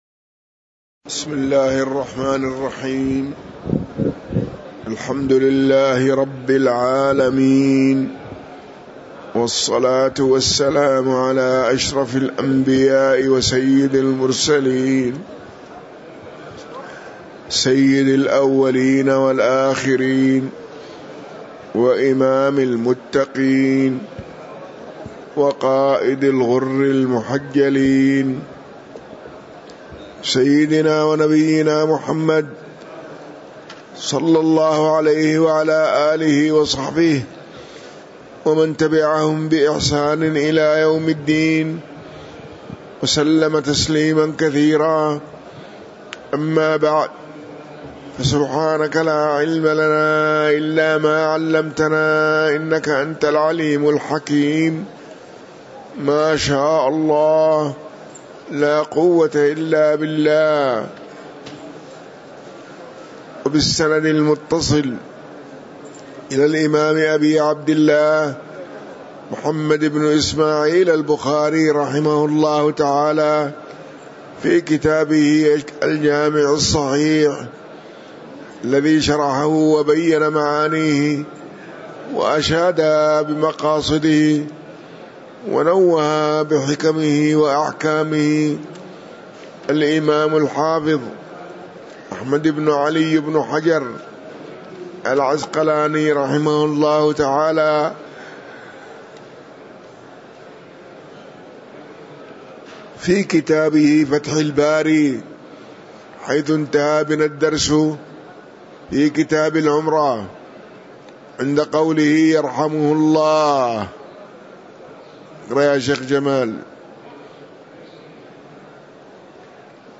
تاريخ النشر ٥ رمضان ١٤٤٤ هـ المكان: المسجد النبوي الشيخ